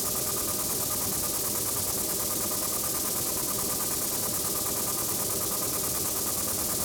STK_MovingNoiseB-140_02.wav